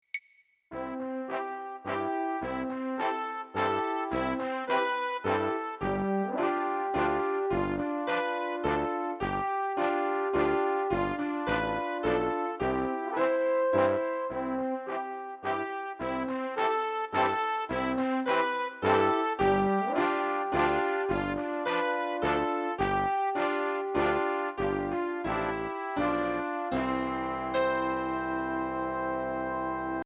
Voicing: Electric Piano